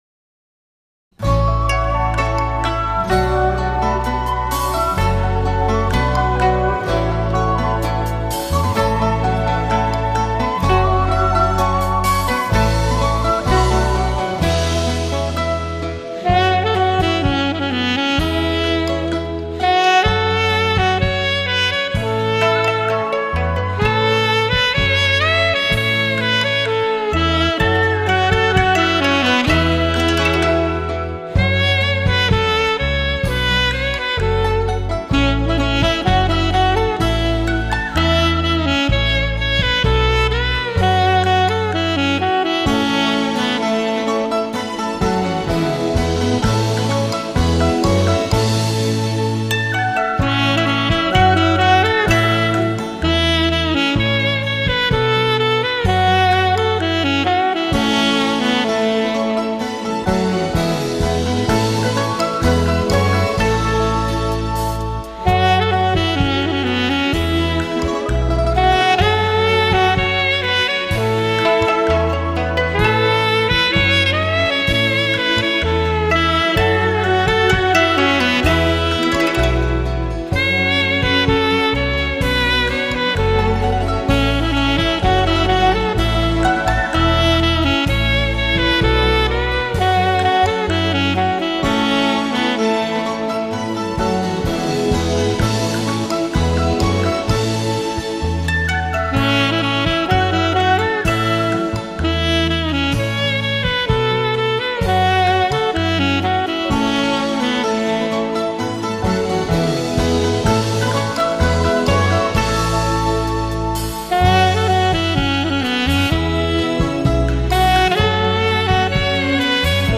曲调悠扬动听、流畅深远，音色通透鲜明、浓郁至美，层次绝佳、传真度至高，非常的抒情。